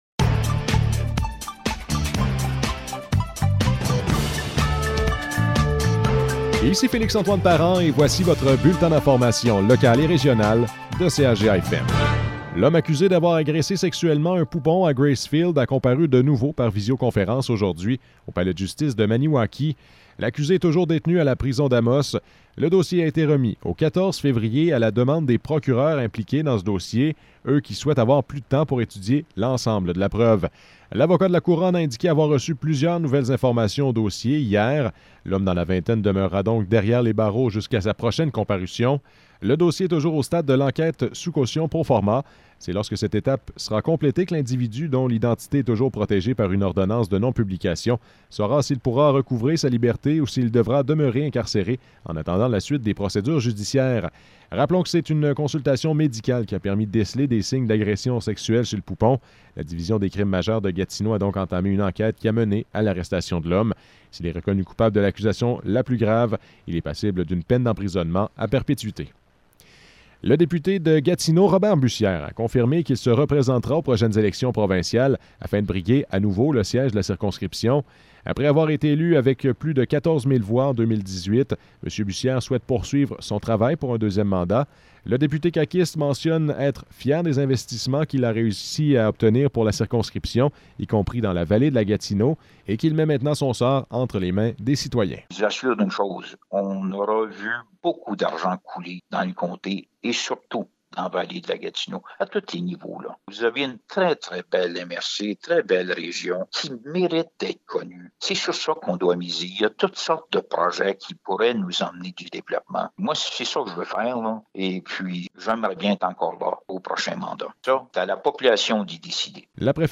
Nouvelles locales - 27 janvier 2022 - 12 h